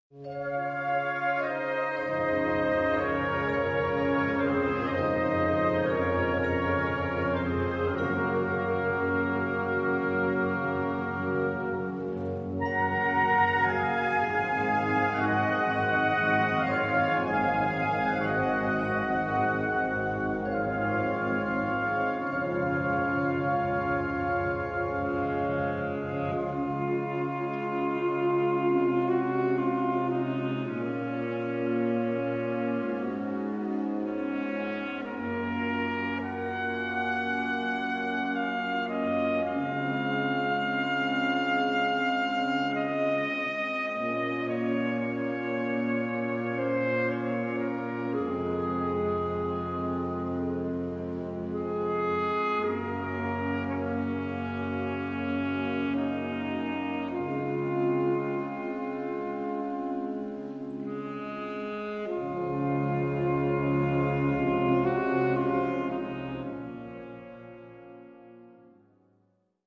Gattung: Filmmusik
Besetzung: Blasorchester